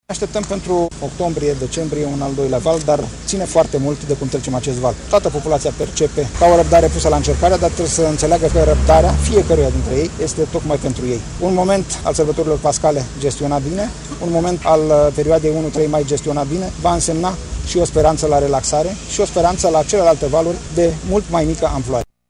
Ministrul Sănătăţii, Nelu Tătaru spune că amploarea acestei noi perioade ar putea fi mult mai mică, însă totul depinde de cum vor fi respectate recomandările de distanţare socială. De asemenea, ministrul îndeamnă la atenție și grijă în perioada următoare: